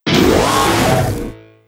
P3D-Legacy / P3D / Content / Sounds / Cries / 849_amped.wav